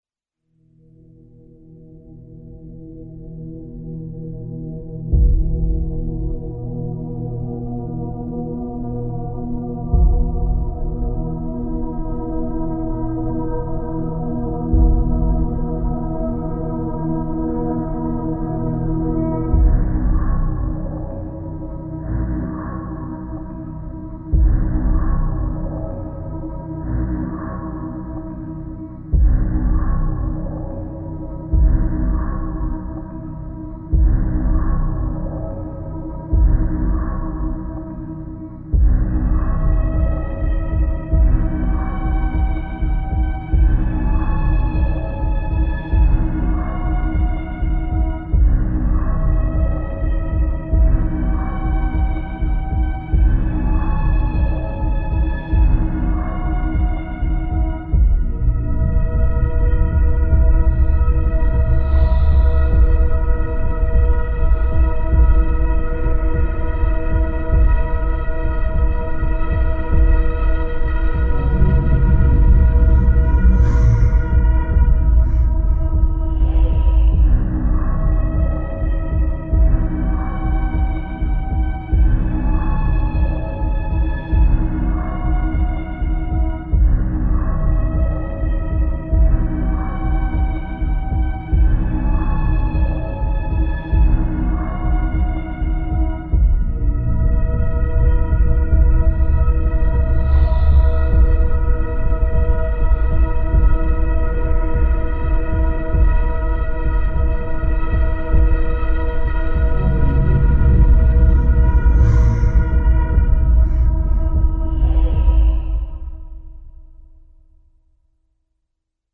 Creepy Ambo